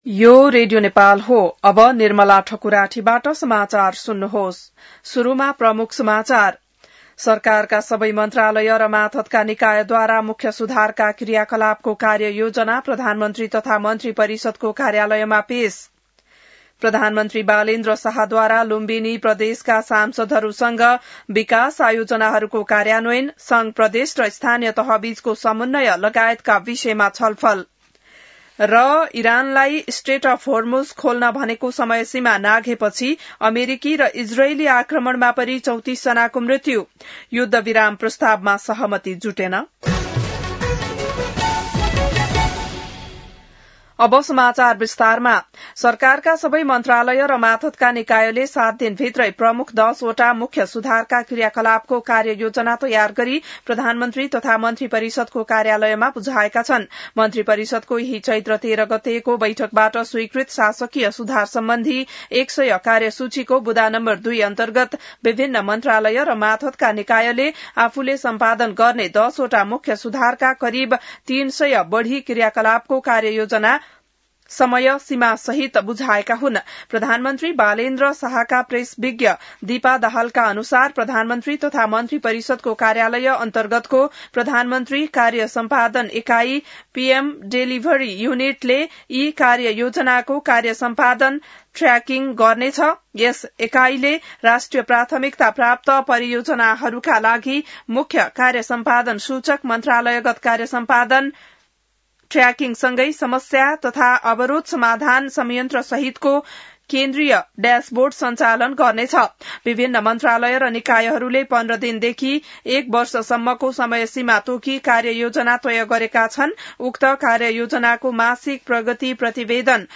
बिहान ९ बजेको नेपाली समाचार : २४ चैत , २०८२